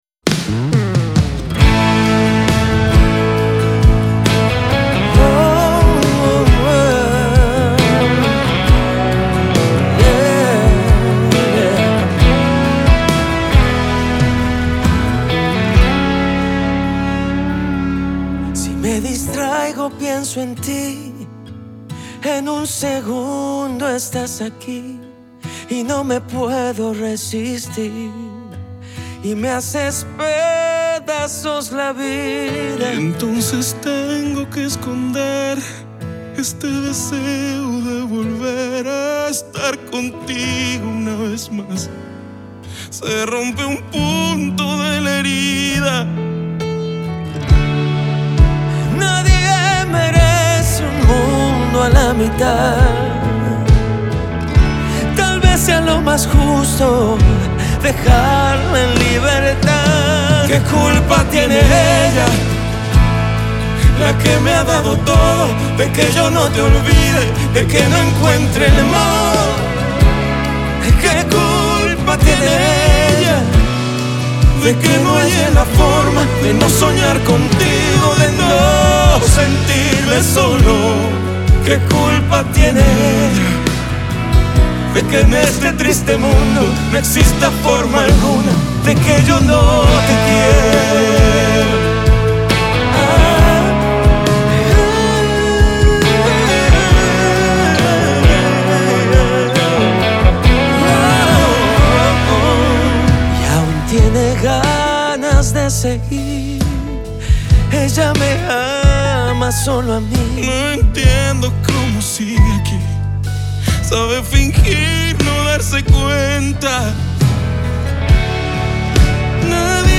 El dúo más importante de la música romántica en español